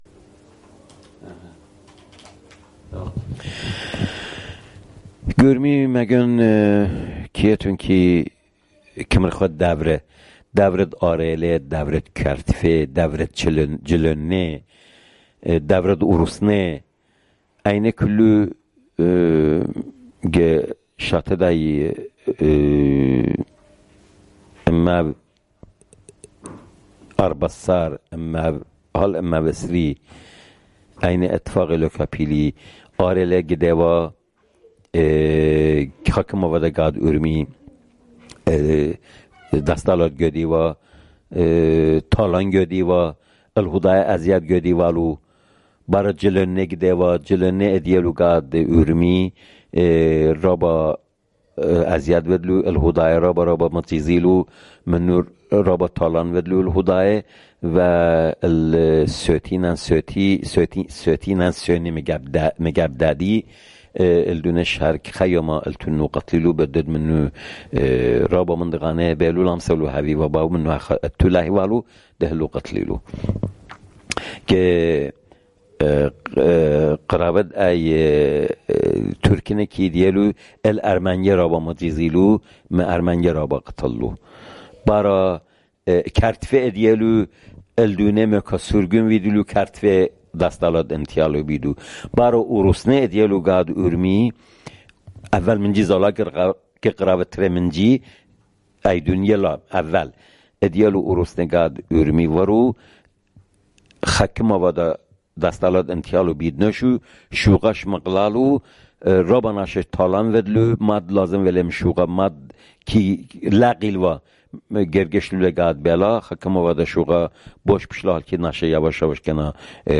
Urmi, Jewish: Life in War-Time